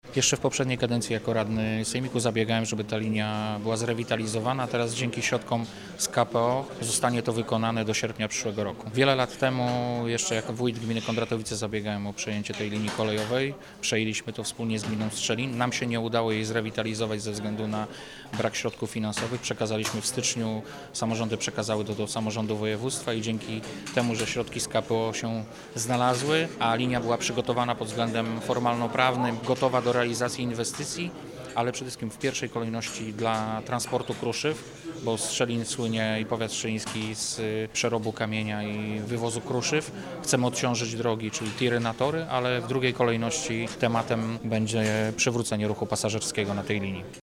– Ta linia kolejowa jest ważna zarówno dla samorządów, ale przede wszystkim dla firm. W pierwszej kolejności będą się nią przemieszczać transporty towarowe, ale docelowo możliwe będzie wpięcie jej w sieć połączeń pasażerskich – mówi wicemarszałek Wojciech Bochnak.